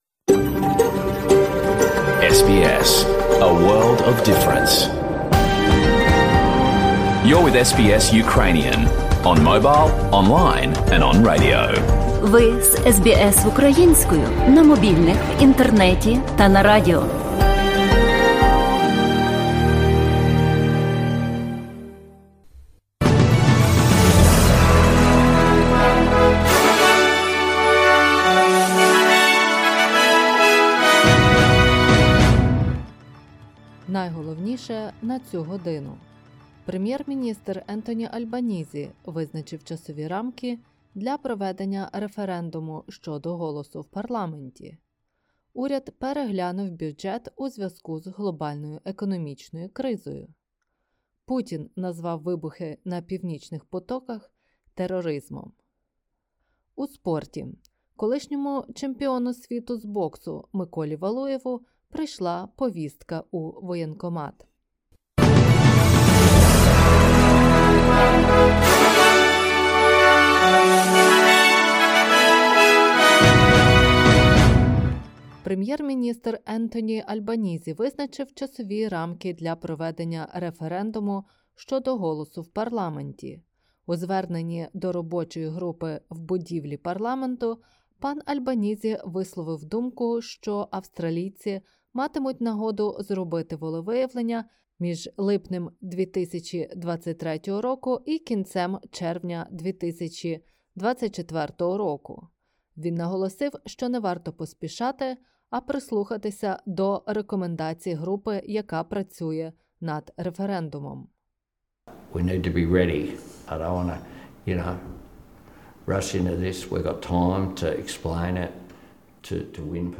SBS News in Ukrainian – 30/09/2022